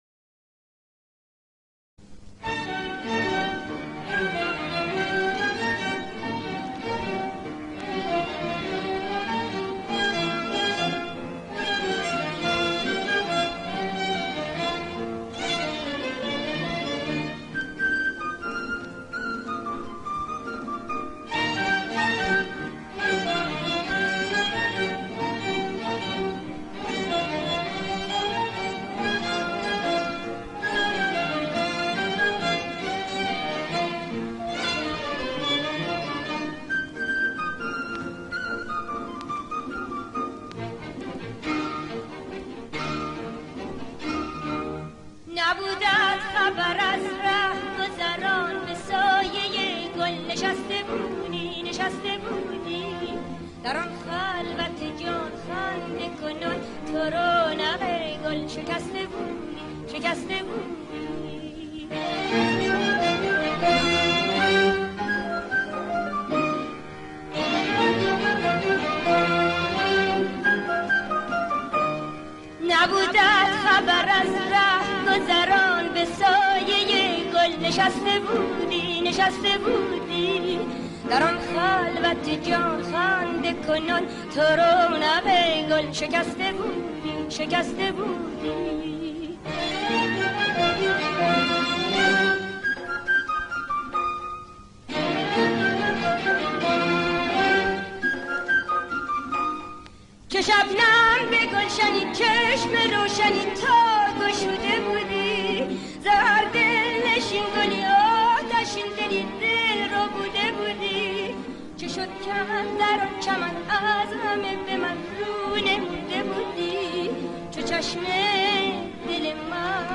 دستگاه: ماهور